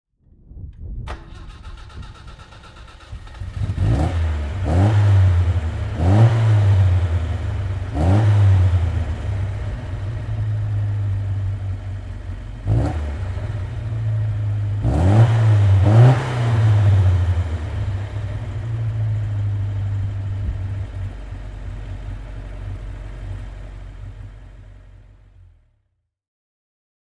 Alfa_Romeo_Giulia_Spider_1962.mp3